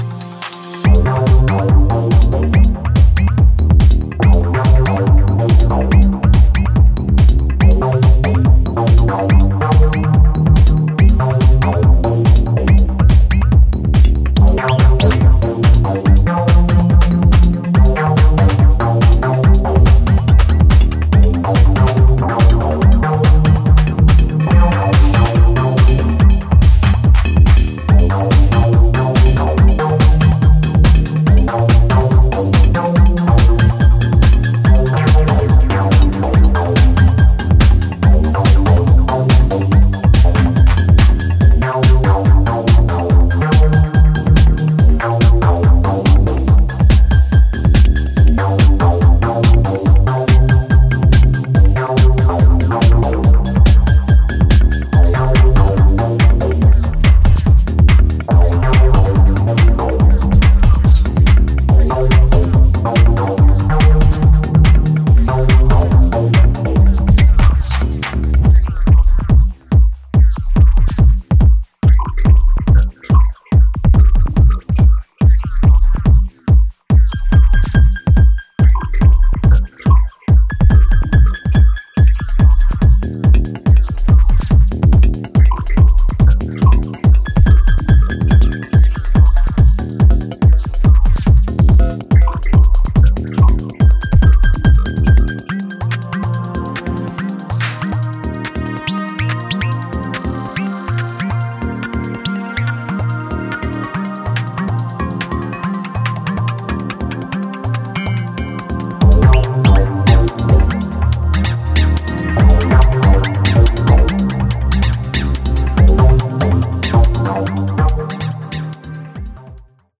全編非常に歯切れの良いハイテンポのグルーヴが走るモダン・テック・ハウスで痛快そのもの。